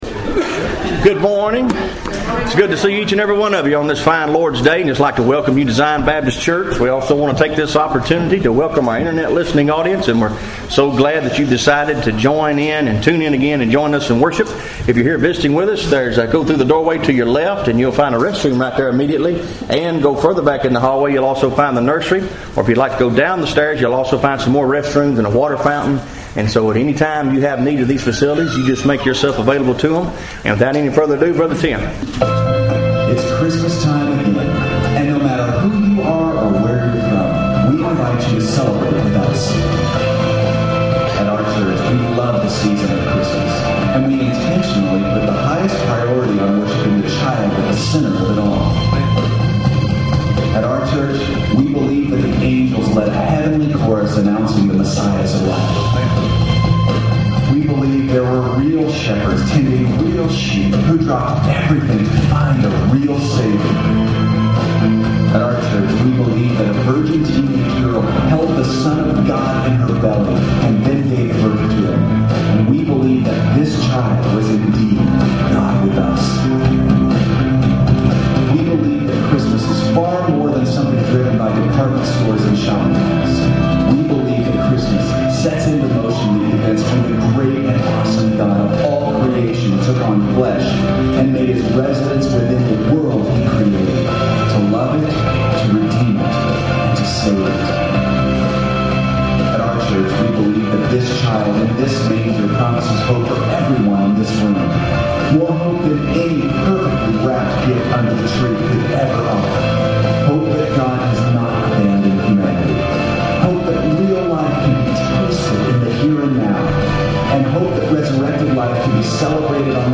Zion Baptist Church Sermons